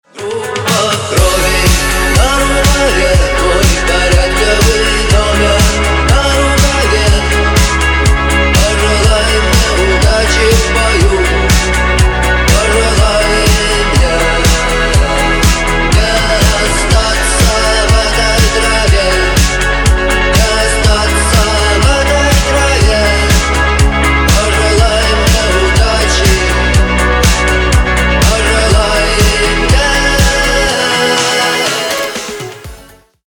• Качество: 256, Stereo
мужской вокал
deep house
dance
Electronic
EDM
club